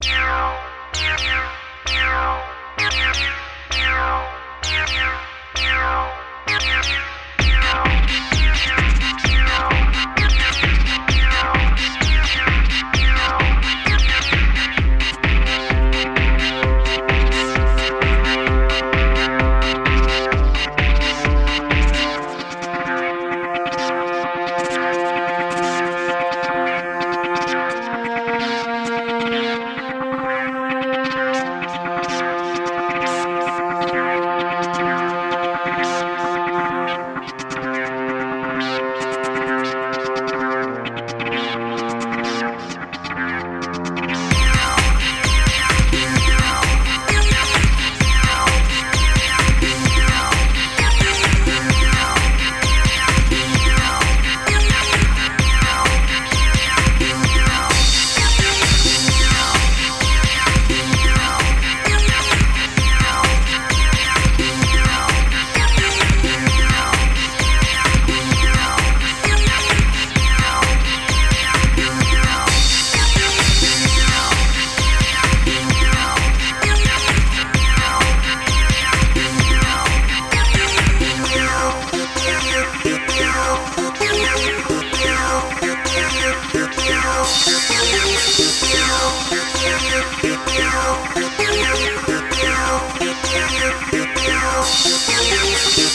Pack from 1 to 5 [LOW quality].